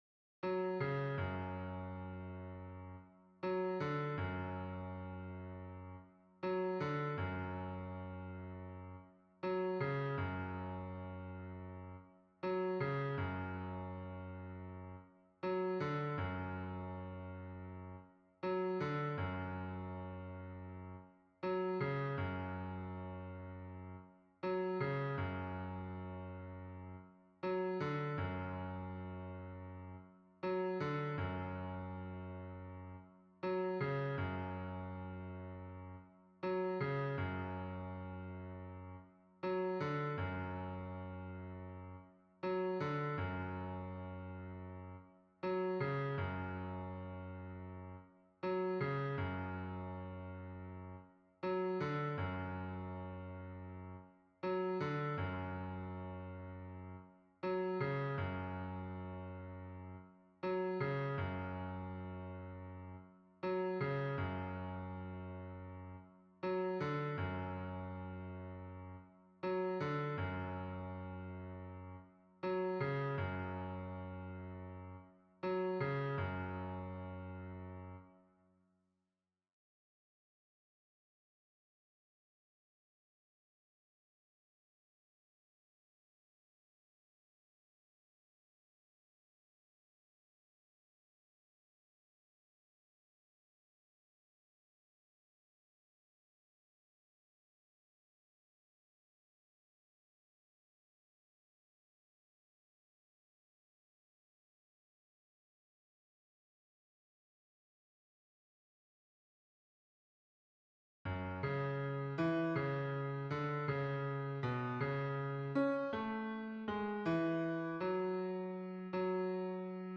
- berceuse traditionnelle norvégienne
MP3 version piano
Basse